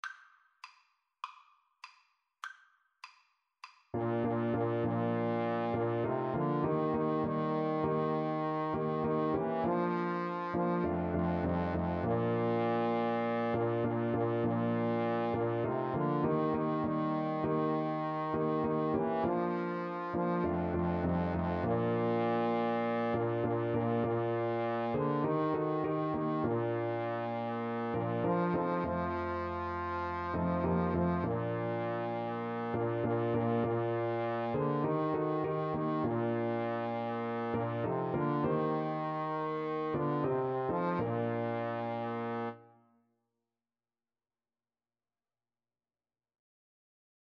(with piano)